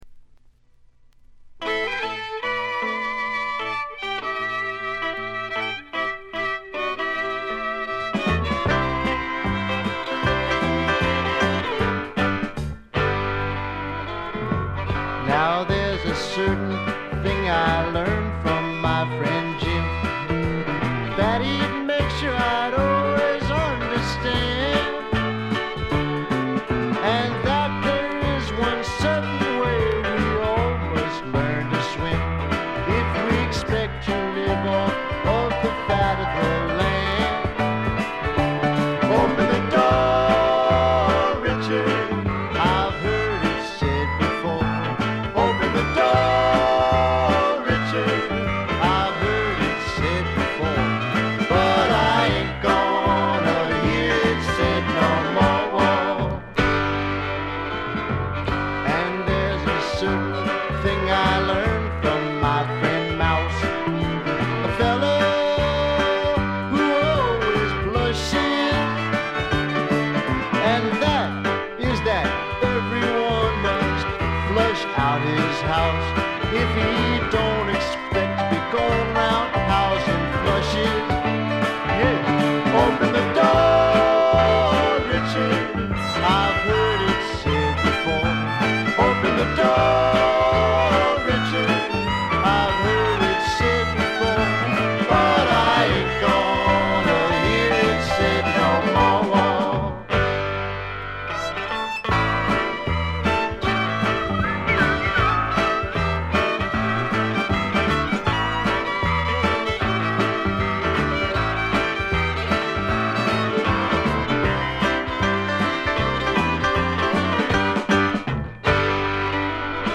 軽微なチリプチ程度。
試聴曲は現品からの取り込み音源です。
Recorded At - Sound Exchange Studios